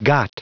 Prononciation du mot got en anglais (fichier audio)
Prononciation du mot : got